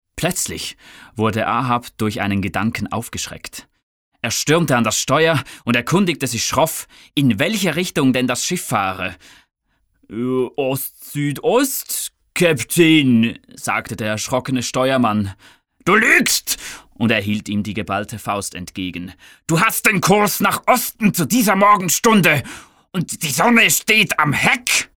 Beispiel Belletristik